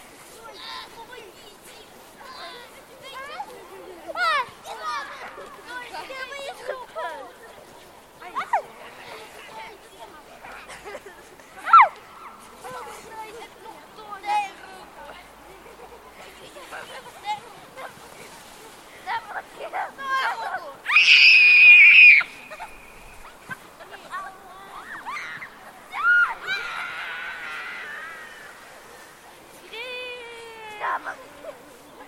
Звуки детского сада
Дети весело играют на площадке детского сада под открытым небом